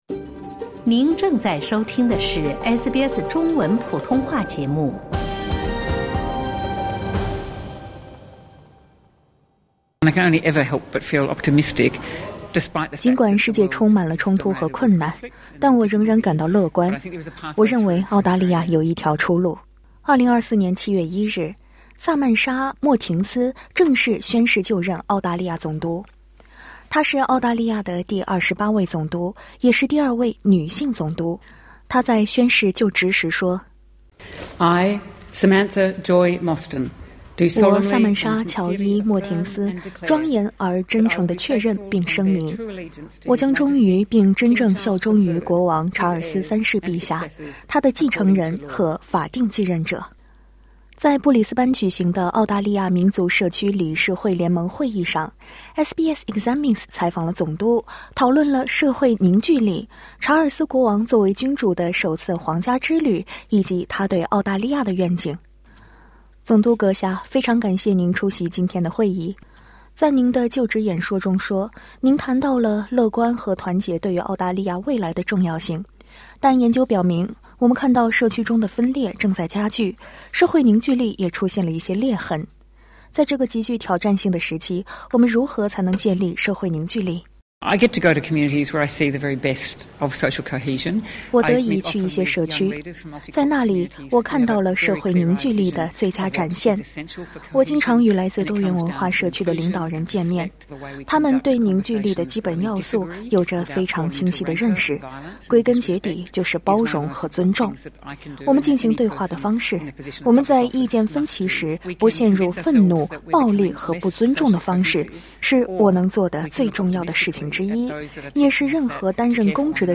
（点击上方收听音频） 在与SBS Examines的采访中，总督萨曼莎·莫斯廷分享了她对社会凝聚力、乐观主义和君主制角色方面的看法。
在布里斯班举行的澳大利亚民族社区理事会联盟会议（Foundation of Ethnic Communities Council Conference）上，SBS Examines采访了总督，讨论了社会凝聚力、查尔斯国王作为君主的首次皇家之旅以及她对澳大利亚的愿景。